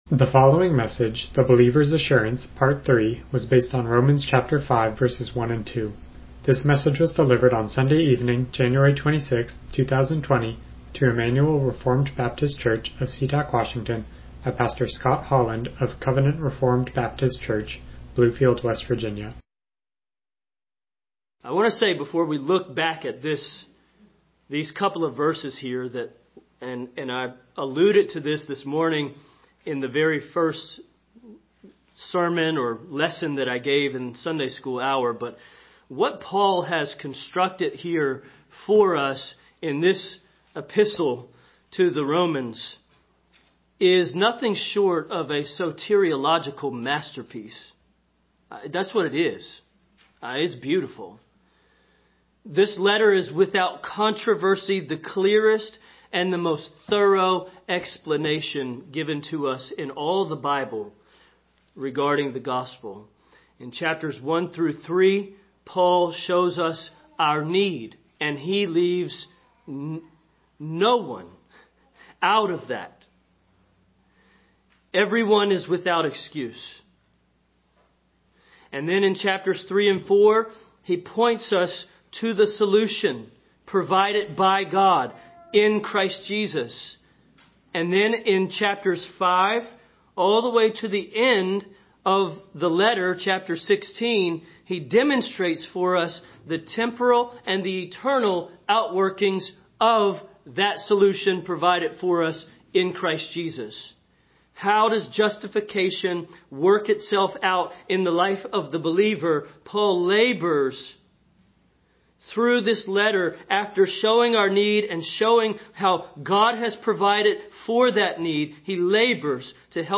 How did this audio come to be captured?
Romans 5:1-2 Service Type: Evening Worship « The Believer’s Assurance